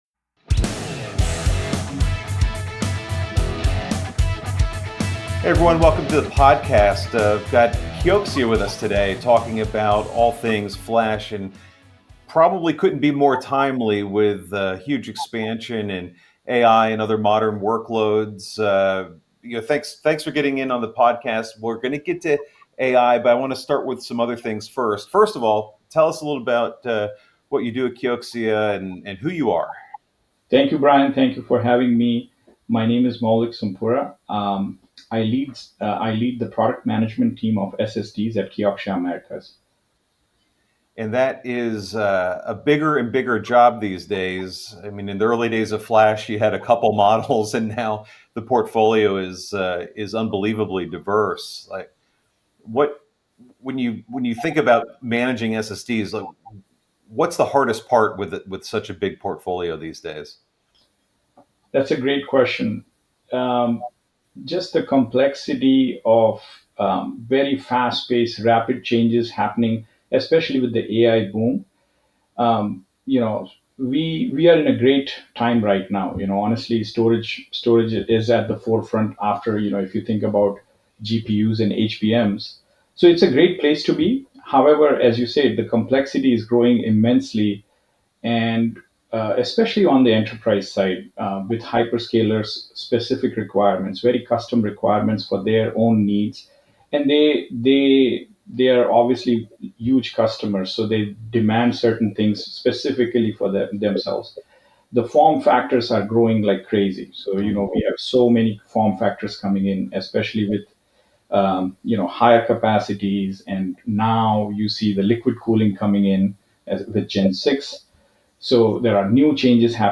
It is an informative conversation that will prompt you to think about something in the long term.